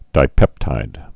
(dī-pĕptīd)